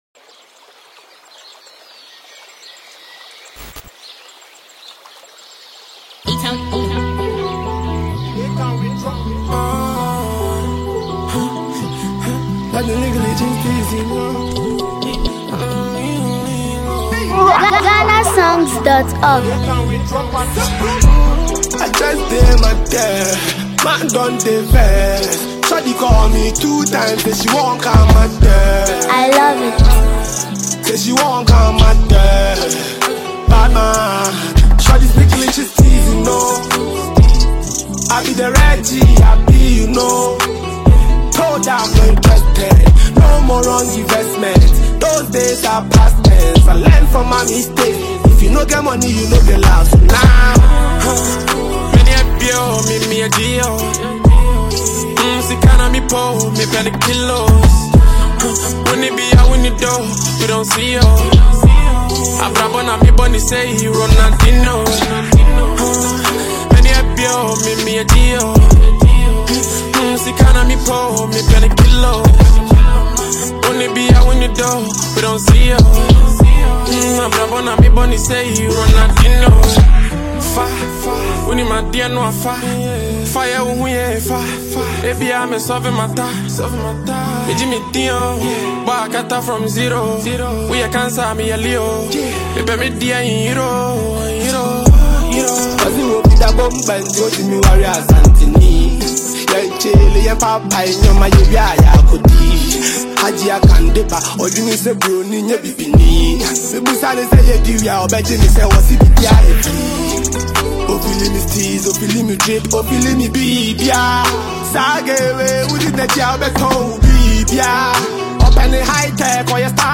the song blends energetic rap with catchy melodies
smooth melodies and a catchy hook